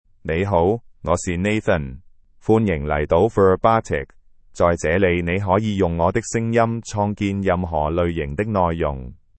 Nathan — Male Chinese (Cantonese, Hong Kong) AI Voice | TTS, Voice Cloning & Video | Verbatik AI
Nathan is a male AI voice for Chinese (Cantonese, Hong Kong).
Voice sample
Listen to Nathan's male Chinese voice.
Male
Nathan delivers clear pronunciation with authentic Cantonese, Hong Kong Chinese intonation, making your content sound professionally produced.